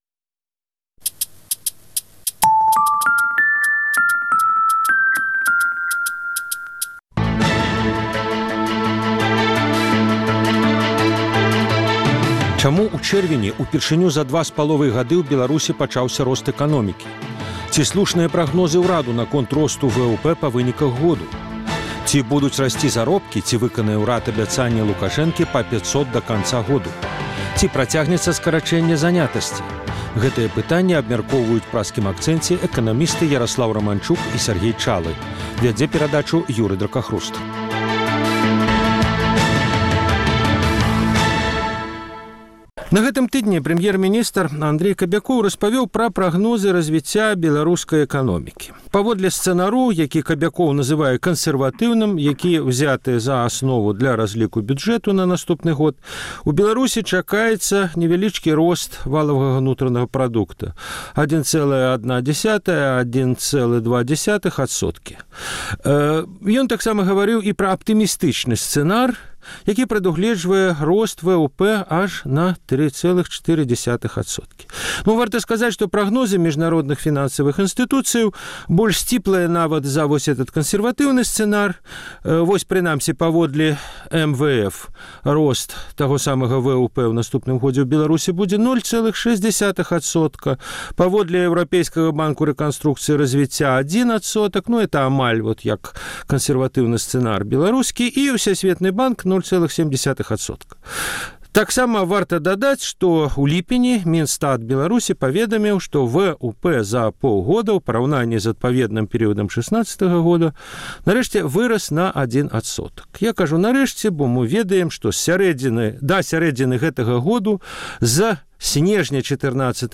Гэтыя пытаньні абмяркоўваюць у Праскім акцэнце эканамісты